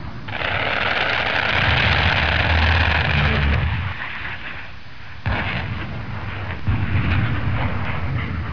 دانلود آهنگ طیاره 38 از افکت صوتی حمل و نقل
جلوه های صوتی
دانلود صدای طیاره 38 از ساعد نیوز با لینک مستقیم و کیفیت بالا